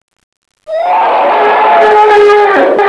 Elephant
ELEPHANT.wav